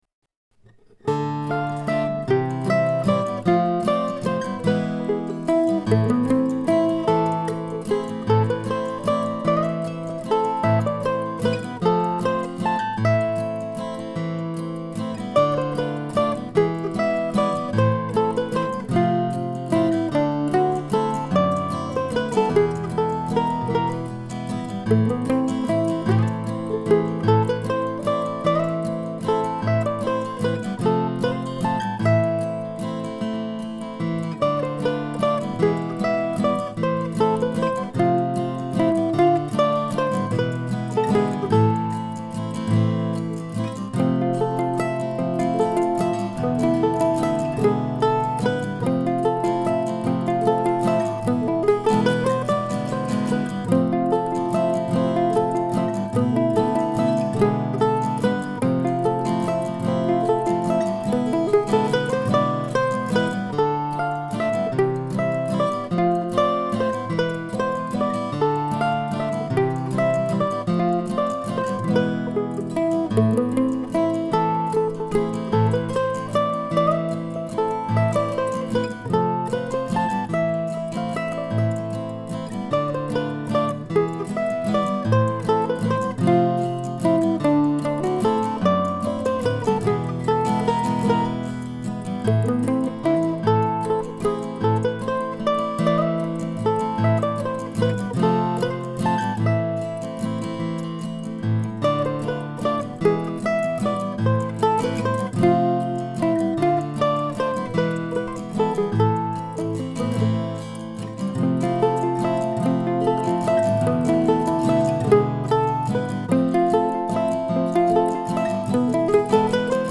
This year I came up with (among other things) a waltz that I'm calling "The Sock Thief."
On Thursday morning I sat down on the bed with my mandolin and, after playing bits of some of the tunes in the Timeless book, I set out to write a simple, but interesting, tune.
I turned off my inner editor ("Oh please, not another A minor arpeggio!") and had a lot of fun.
On the technical side, if you sometimes suffer from the "other" MAS (in this case "microphone acquisition syndrome", rather than the more common "mandolin acquisition syndrome") you might be interested to know that I used a brand new MXL 991 mic to record my old Gibson (still with the same Thomastik strings I put on back in September).